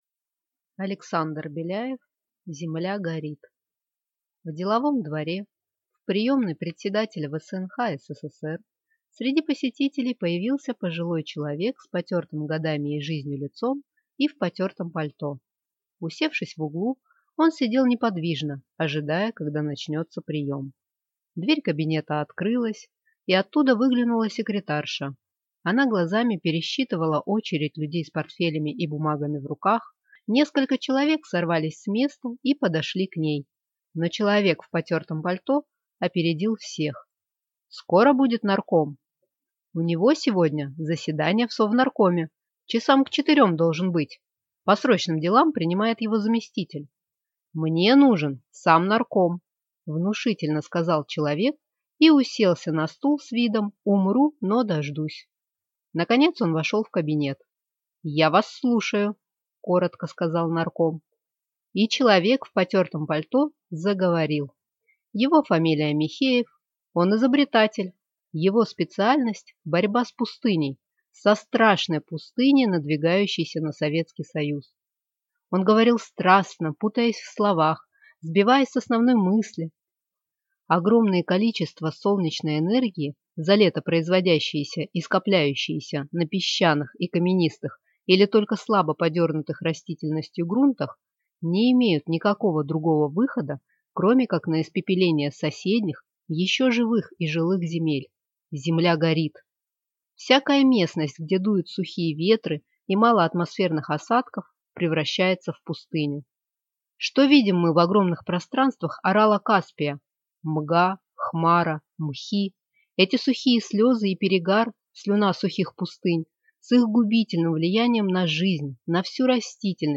Аудиокнига Земля горит | Библиотека аудиокниг